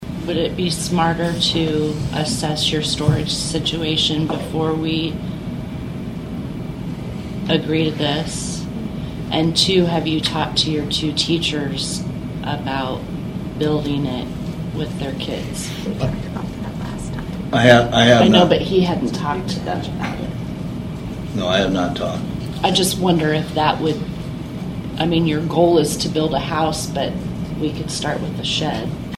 (Atlantic) The Atlantic School Board, at Wednesday’s meeting, tabled the Architect/Engineer contract of a new high school storage shed.
School Board member Jenny Williams moved to table the proposal pending further discussion with the building and trades instructors to see if this would be a viable project for the students.